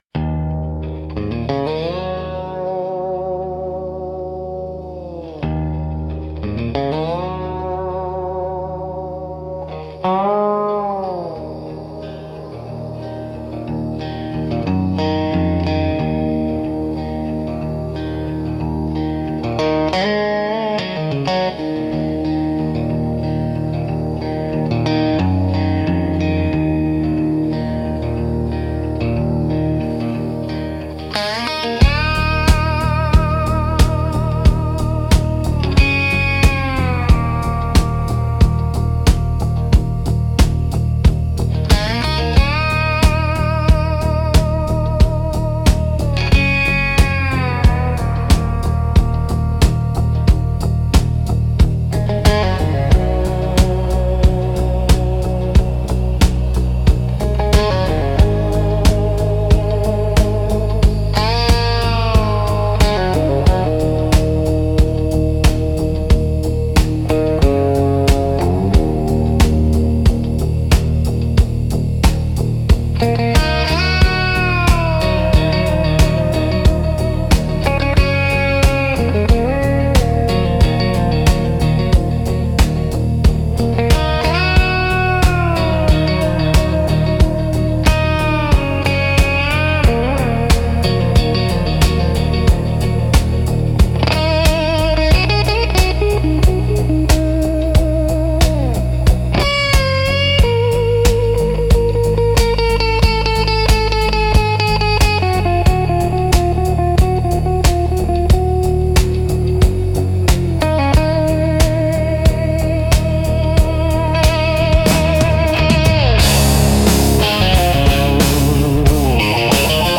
Instrumental - Untitled (Endless Highway) 3.32